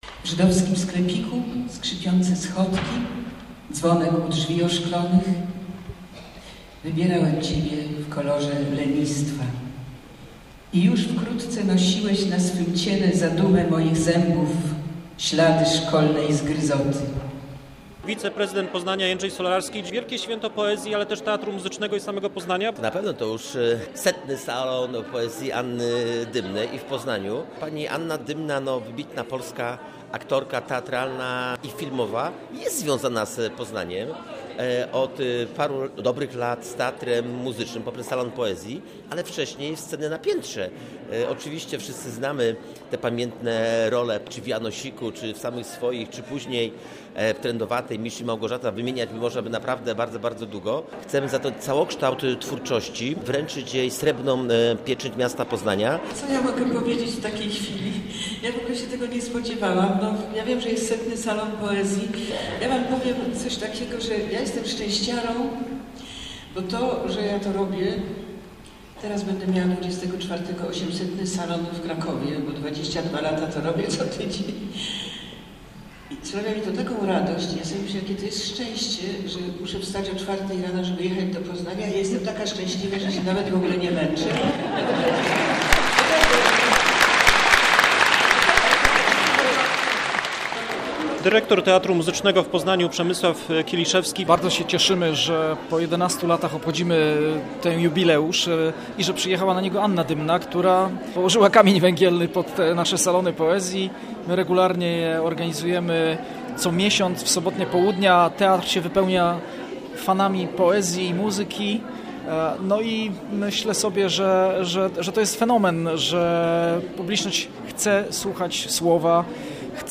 Anna Dymna odebrała w Poznaniu Srebrną Pieczęć. Recytując wiersze polskich poetów uczestniczyła w setnym Salonie Poezji.
Niedzielny wieczór był prawdziwym świętem poezji, wspaniałej recytacji słuchała pełna aula UAM.